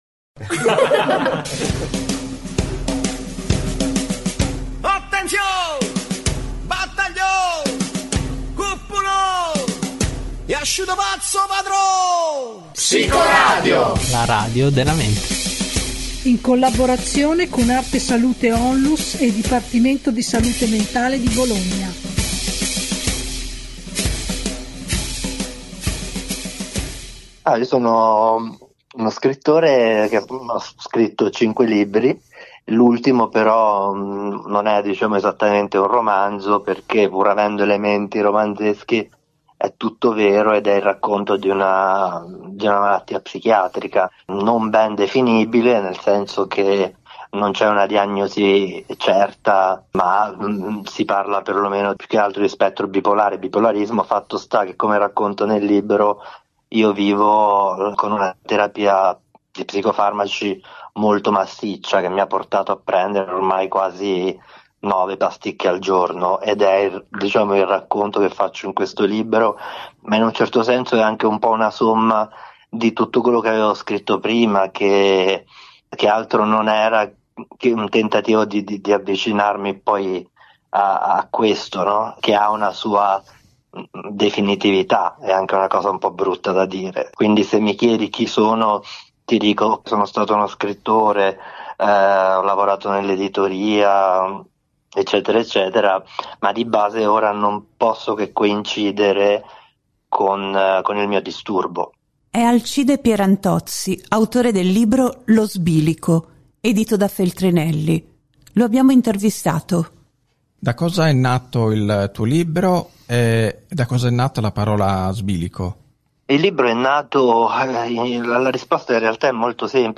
Psicoradio lo ha intervistato e ne è uscito un incontro così intenso che abbiamo voluto dedicargli due puntate.